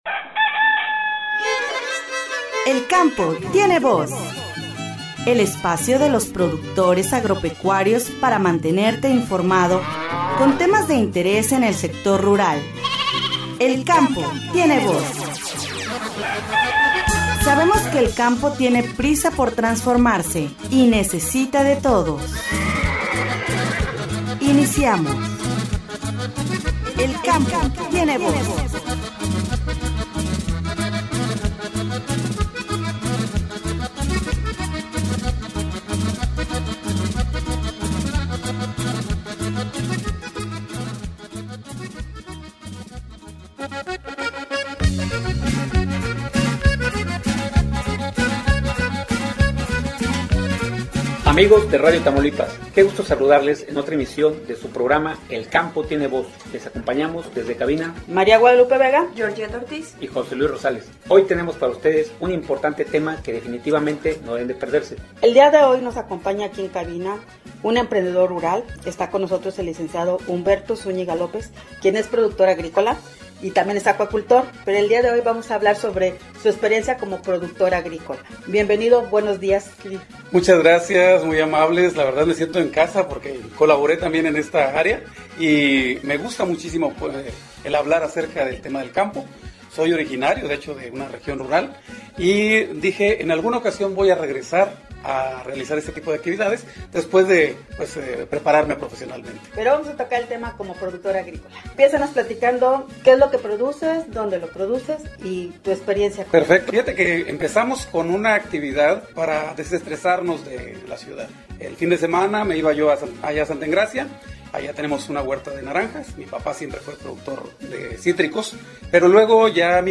“El Campo Tiene Voz”, en esta ocasión se entrevistó al productor
Escúchanos todos los miércoles a las 08:30 de la mañana es esta su estación Radio Tamaulipas.